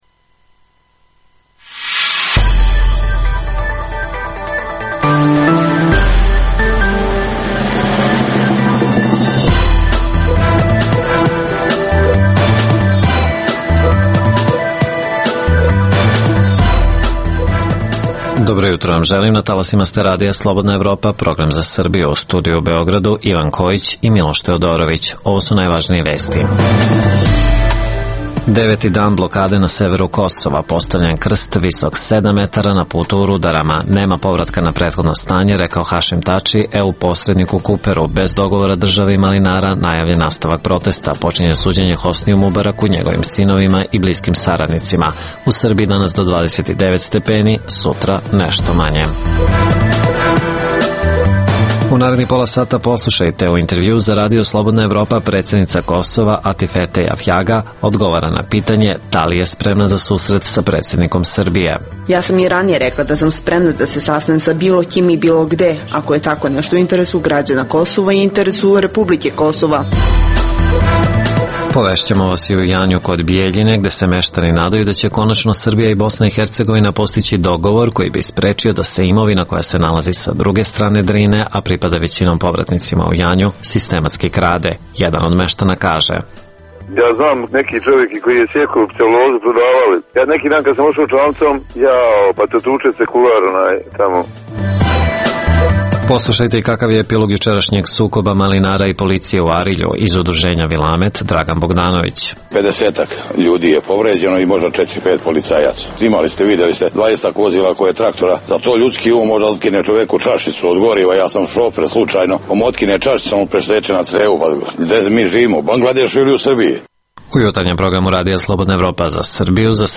U ovoj emisiji: - Predsednica Kosova Atifete Jahjaga u intervjuu za Radio Slobodna Evropa odgovara i na pitanje, da li bi se sastala sa predsednikom Srbije. - Analiziramo, koliko je izvesno da će Beograd iz Brisela dobiti poruke koje očekuje. - Govorimo i o meštanima Janje kod Bijeljine koji konačno mogu raspolagati svojom imovinom u Srbiji.